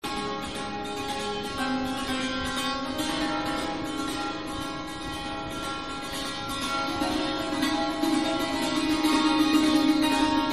シャントゥール-
シャントゥールは合計で約100の弦を持った楽器で、両手に持ったスティックでそれを叩く事で音を出します。非常に幻想的な音を出すので、聞いていて非常に気持ちの良い楽器の一つです。